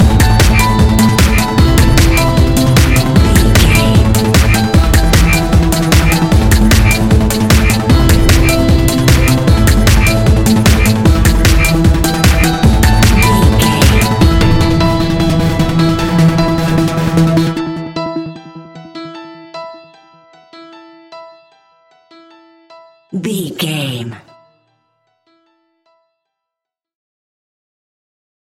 Aeolian/Minor
Fast
meditative
futuristic
hypnotic
piano
drum machine
synthesiser
acid house
electronic
uptempo
synth leads
synth bass